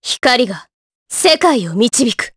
Aselica-Vox_Victory_jp.wav